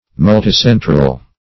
Multicentral \Mul`ti*cen"tral\, a. [Multi- + central.]